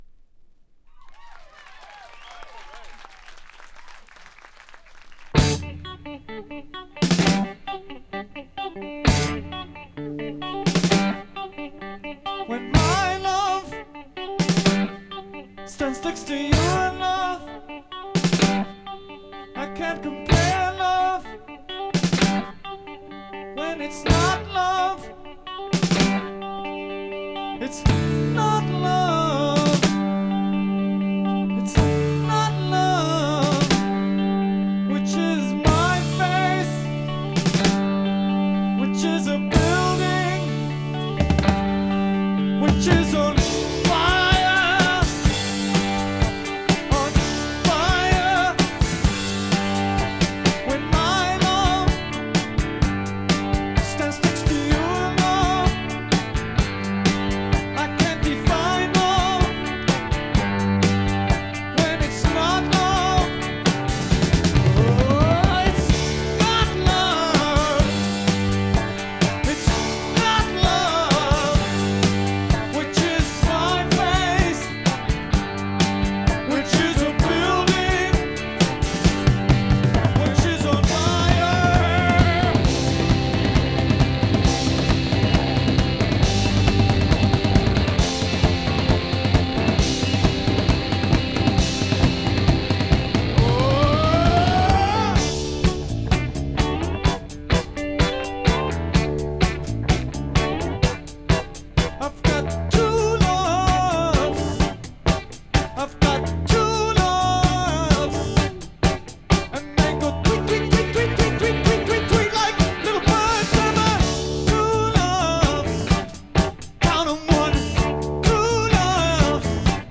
Recorded November 17th, 1977 in Massachusetts
Source: vinyl rip